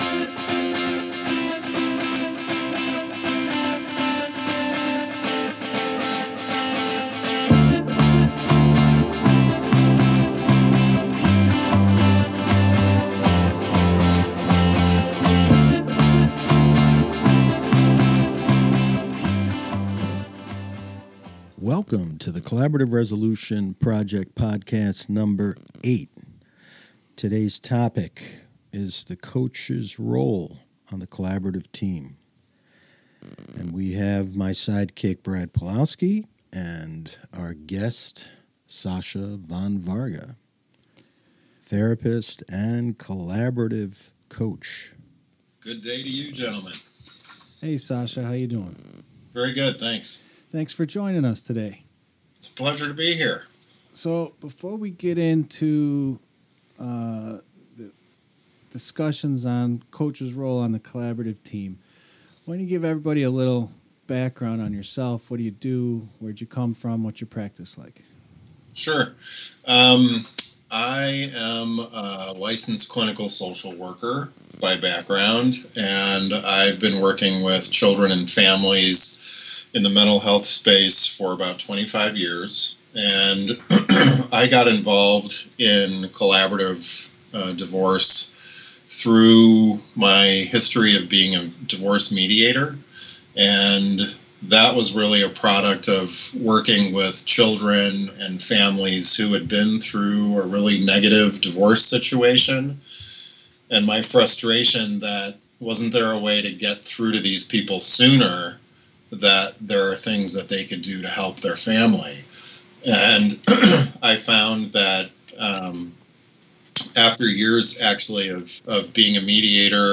We hope you enjoy this conversation and come away with a better appreciation for the value of the coach in the collaborative process.